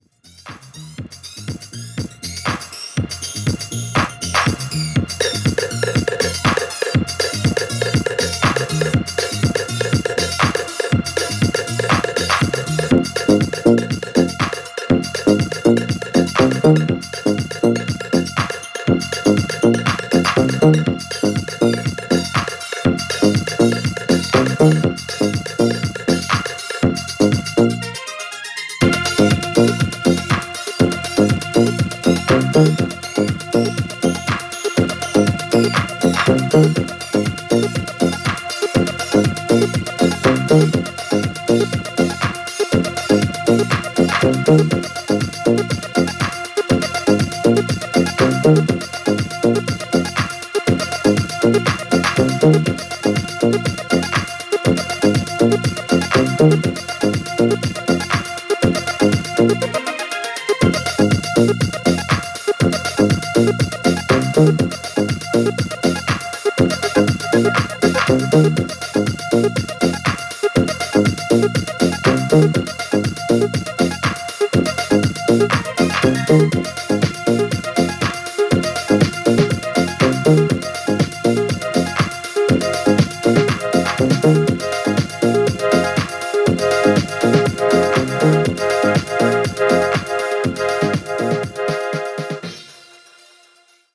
シカゴ感漂う感じがステキ！！！